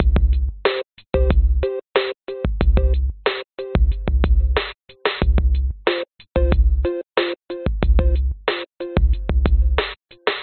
94bpm dragger dagger " 94bpm dragger dagger
描述：当hip_hop是真实和粗糙的时候。创造的原因..........bass.......，并打出。
Tag: 贝斯 击败 臀部 跳跃 理性 粗糙 街道